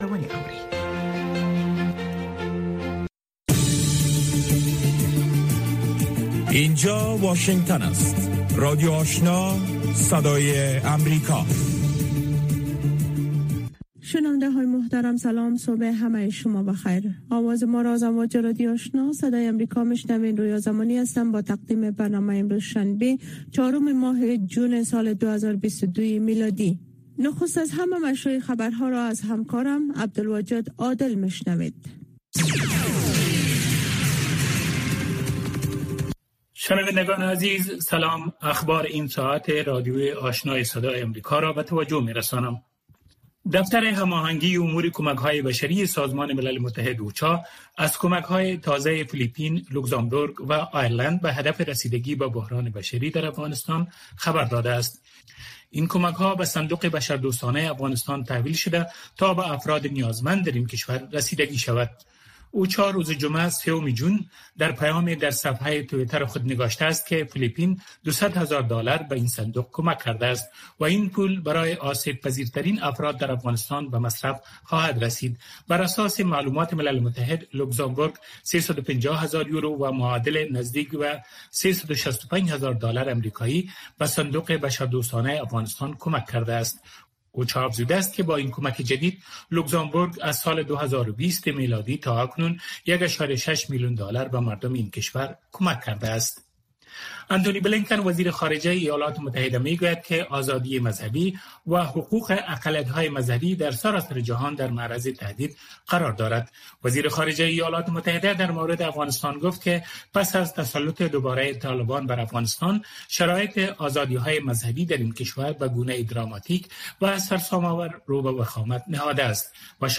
برنامۀ خبری صبحگاهی